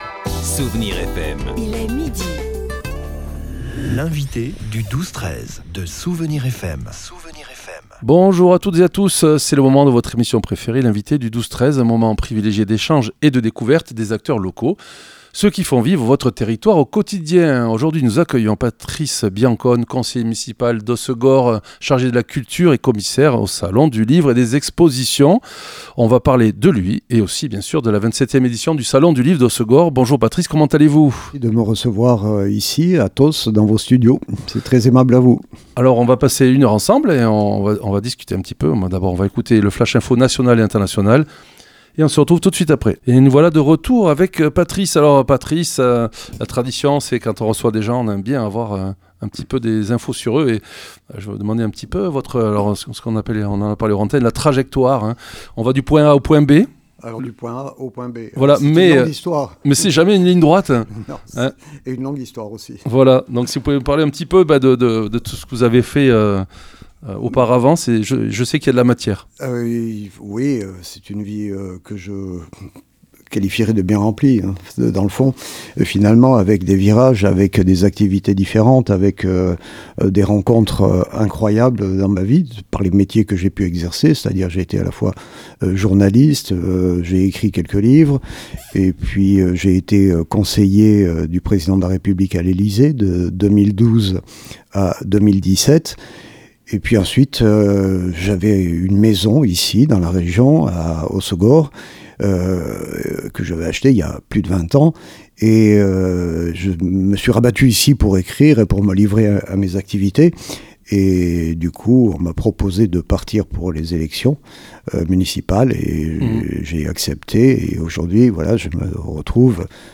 L'invité(e) du 12-13 de Soustons recevait aujourd'hui Patrice Biancone, conseiller municipal à la ville de Soorts-Hossegor, chargé de la culture, commissaire du salon du livre et des expositions.